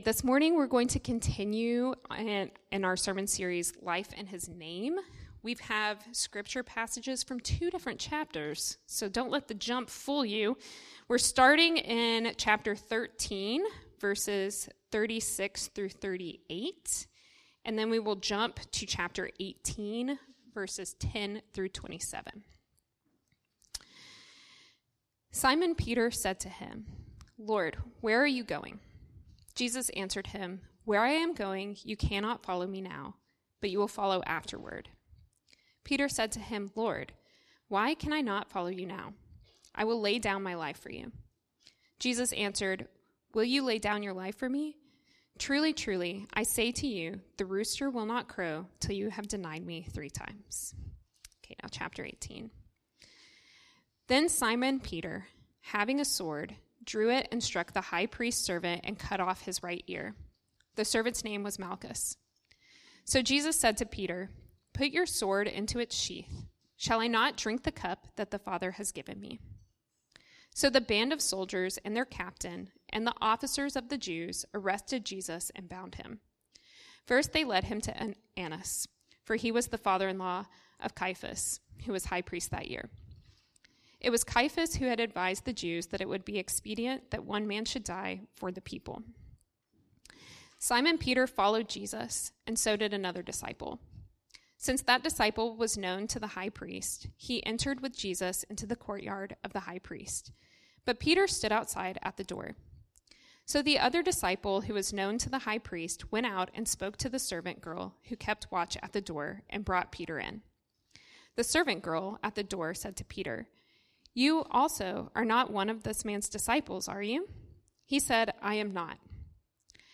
Weekly sermons from Redeemer City Church in Madison, Wisconsin, which seeks to renew our city through the gospel.